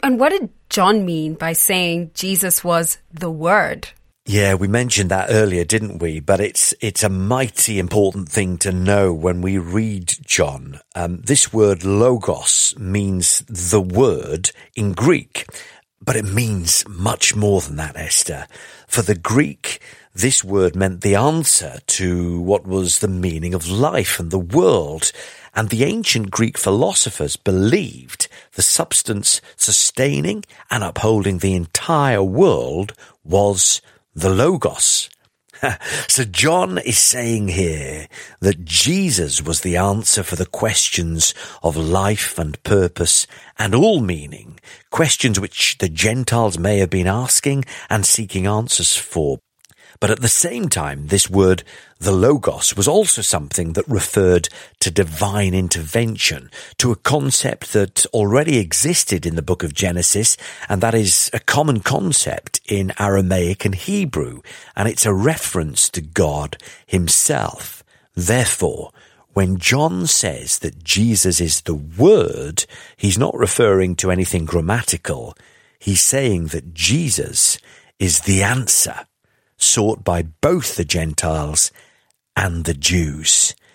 teachers on the daily Bible audio commentary